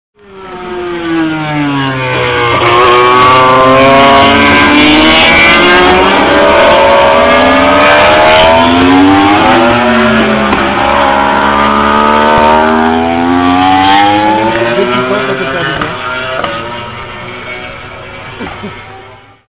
J'en profite pour faire des prises de sons afin d'enregistrer ce bruit rageur que dégagent les MotoGP.
(Lorsqu'on entend le coup de trompe, c'est qu'il s'agit soit de